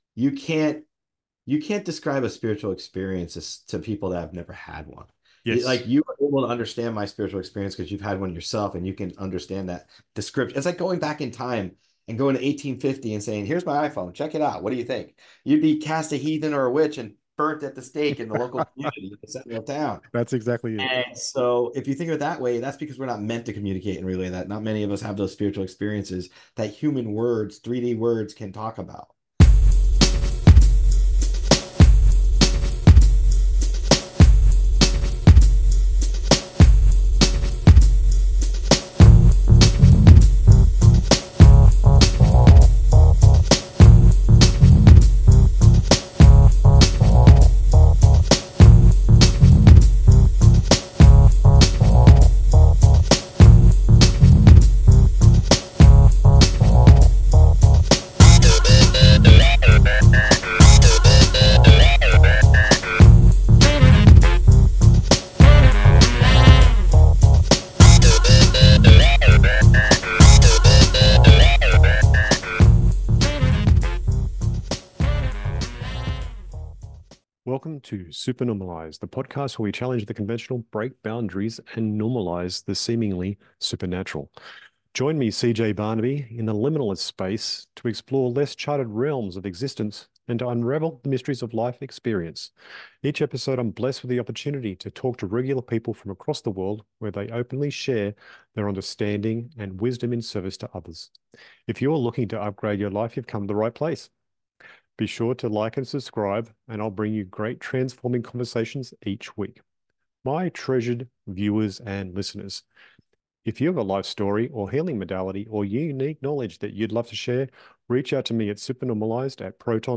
Interview What Is The Life Of An Attorney Psychic Medium Like?